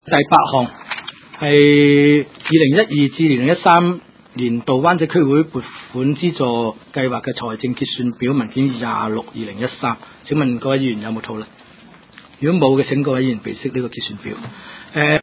区议会大会的录音记录
湾仔区议会会议室